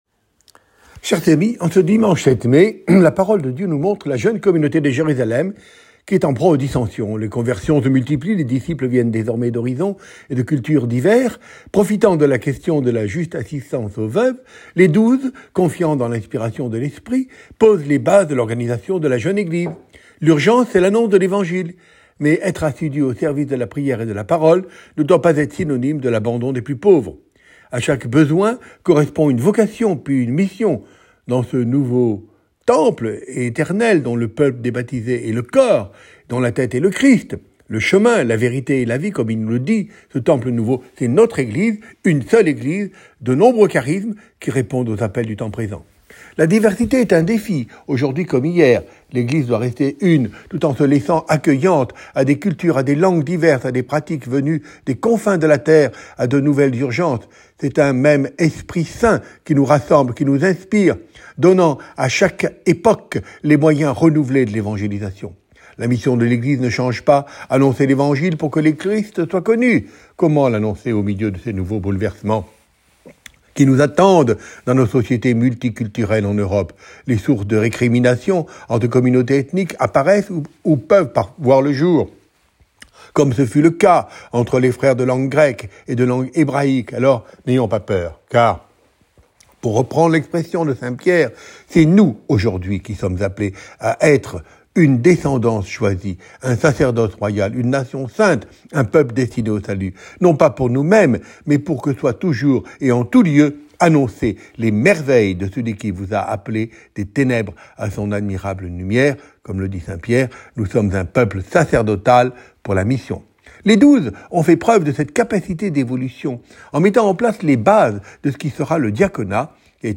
Ecouter l’homélie de Mgr Georges Colomb, Directeur national des OPM